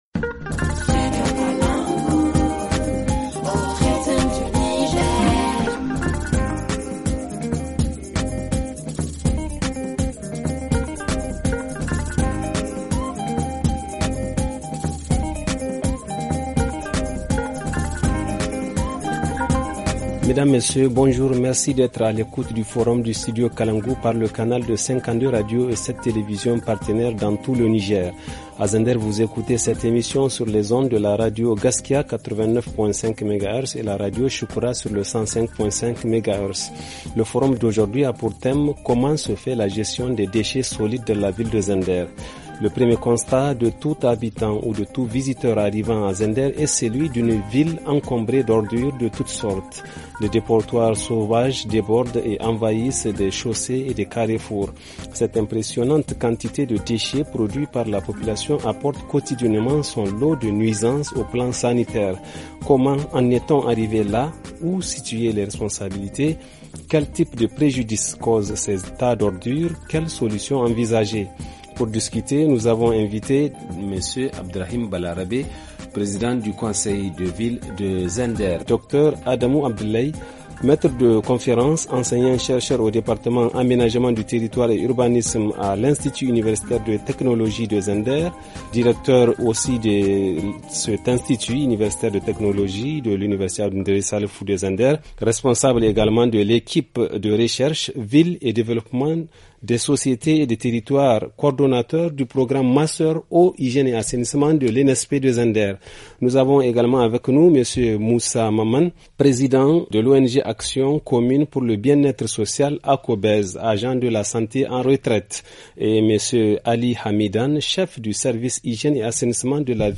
Le forum en français